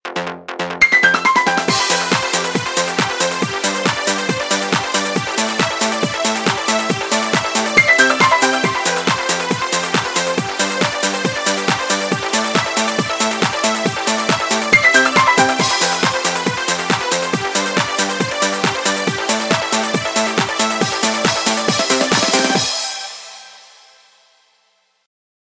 • Качество: 128, Stereo
Стандартный рингтон